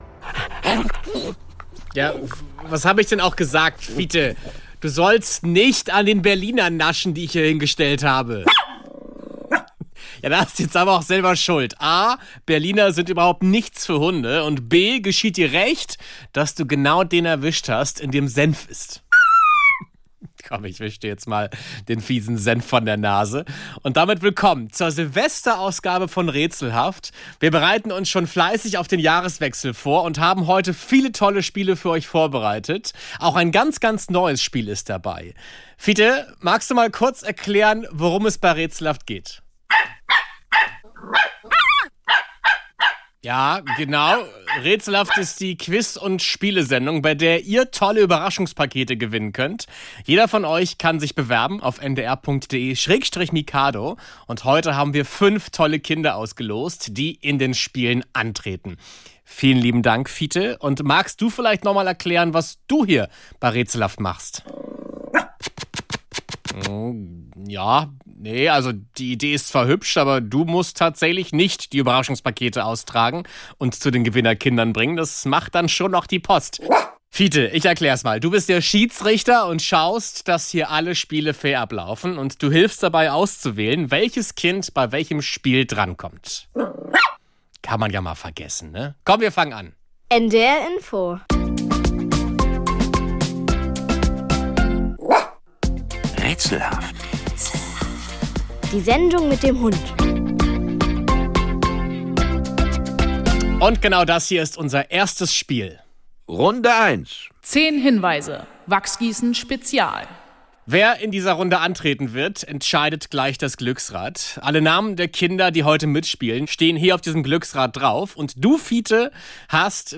In mehreren Raterunden treten Kinder gegeneinander an. Wer ist besser beim Lösen der kniffligen, verdrehten, lustigen Silvester-Rätsel?